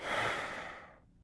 sigh5.wav